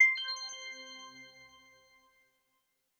Button_4_Pack2.wav